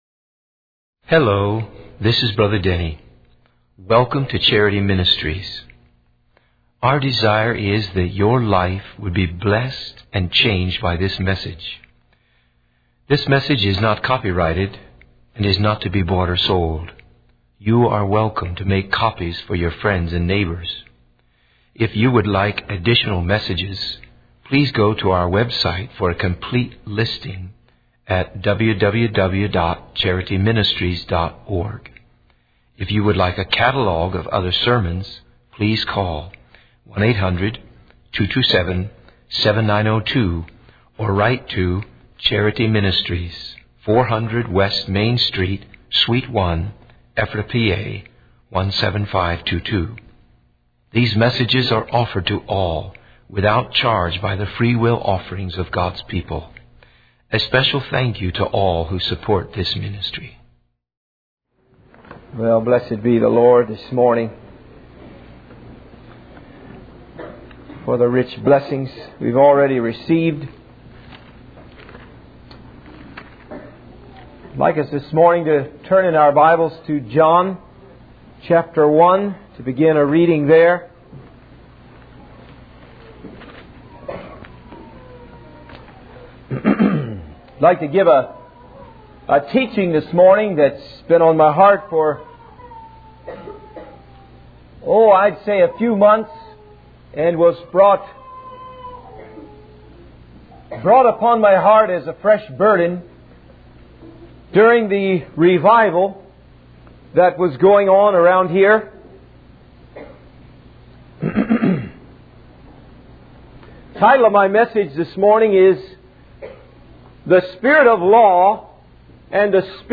In this sermon, the speaker begins by praying for guidance and blessings for the congregation. They emphasize the importance of living in grace rather than under the law, both in personal lives and in the home. The sermon highlights the ministry of grace and truth, which is to bring healing, freedom, and liberation to those who are brokenhearted and bound.